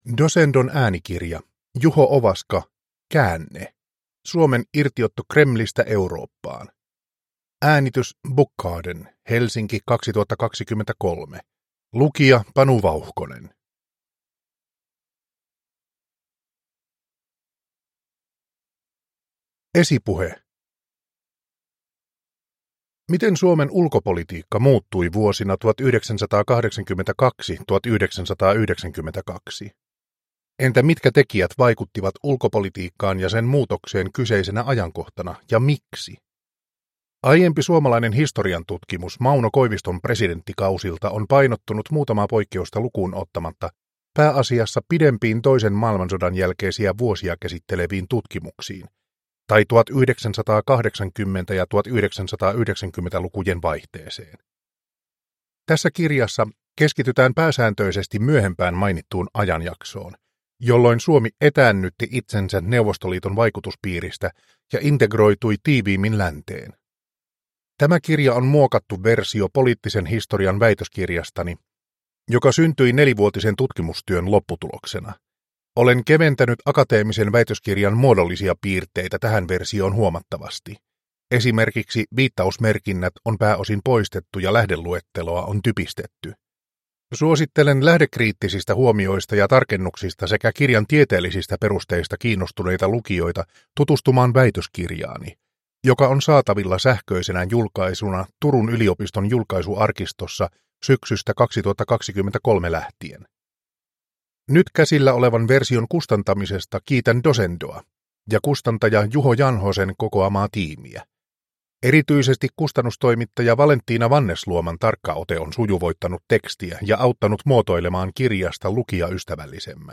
Käänne – Ljudbok – Laddas ner